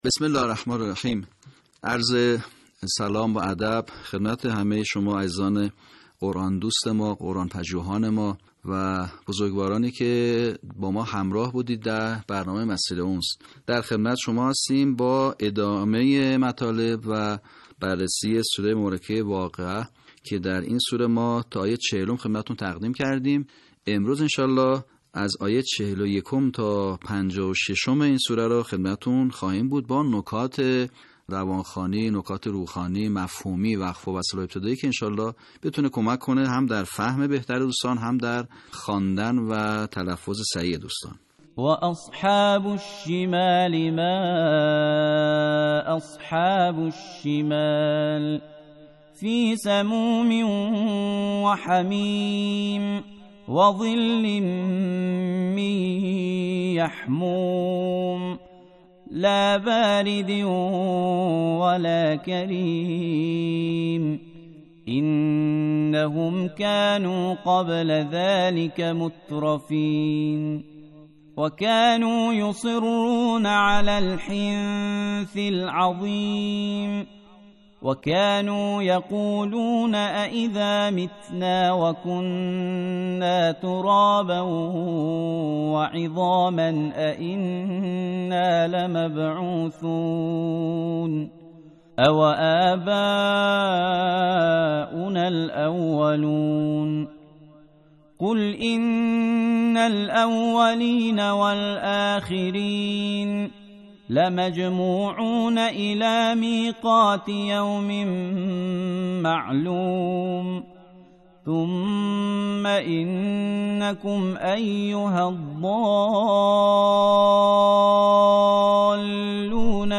صوت | آموزش صحیح‌خوانی آیات ۴۱ تا ۵۶ سوره واقعه
به همین منظور مجموعه آموزشی شنیداری (صوتی) قرآنی را گردآوری و برای علاقه‌مندان بازنشر می‌کند.